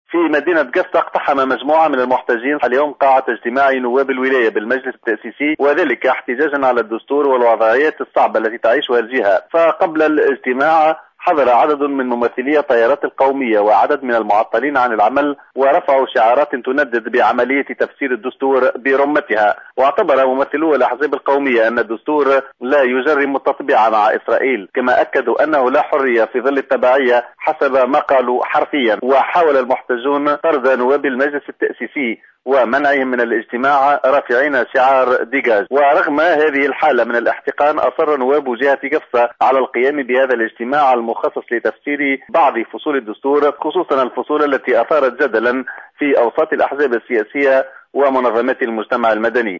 اقتحم اليوم مجموعة من المحتجين مقر ولاية قفصة وقاطعوا الاجتماع الذي يأتي في إطار شهر التعريف بالدستور بحضور نواب الجهة بالمجلس التأسيسي احتجاجا على الدستور والوضعية الصعبة التي تعيشها الجهة وحاولوا طرد النواب من الاجتماع رافعين في وجههم شعار "ديقاج".